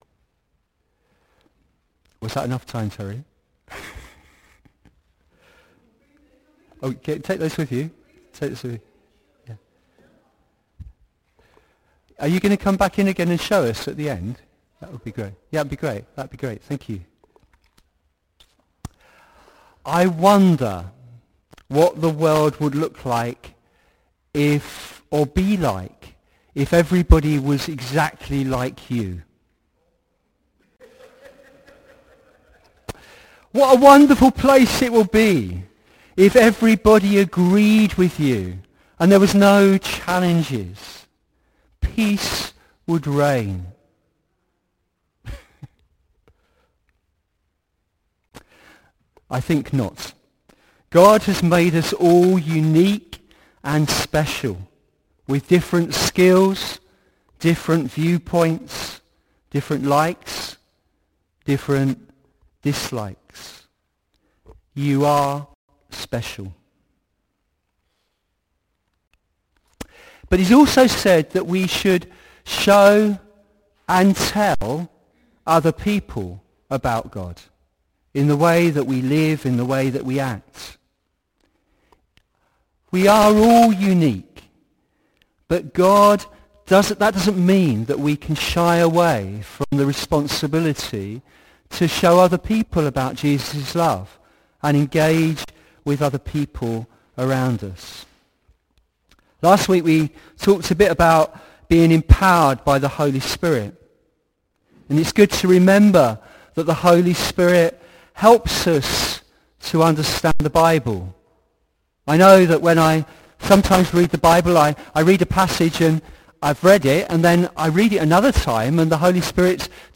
Audio Sermons - Witney Congregational Church
Morning Worship Reflection